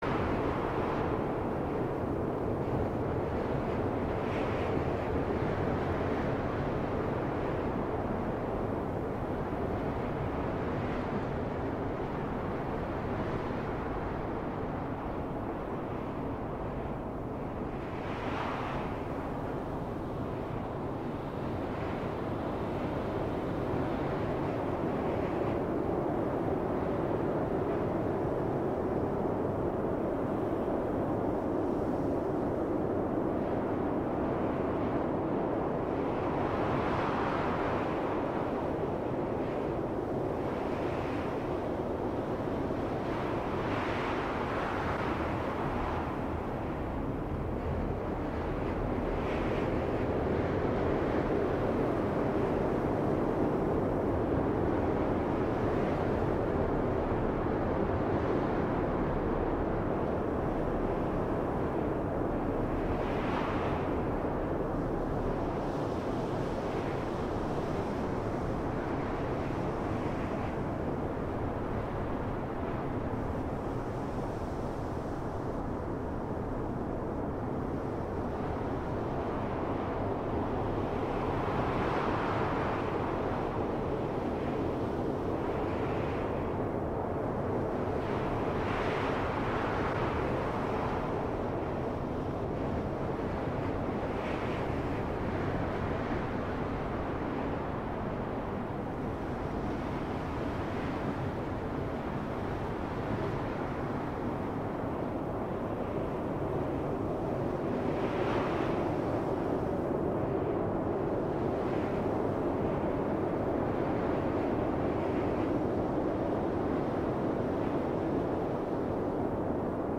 airy-space.ogg